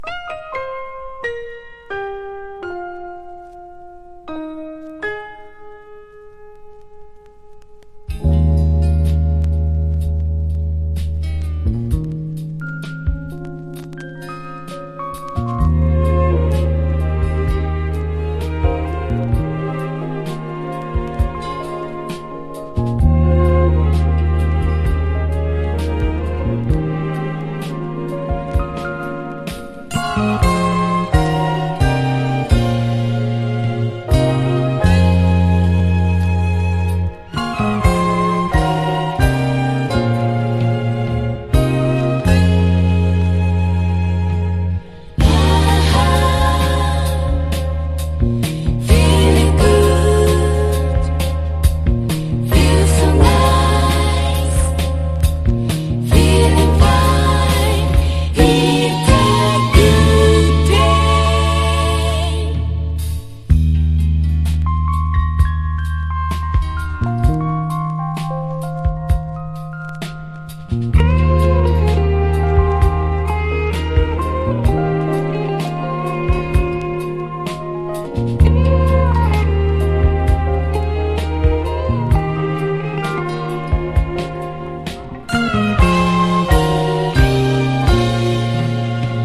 # 和ジャズ# LATIN